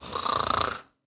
daisy_snoring1.ogg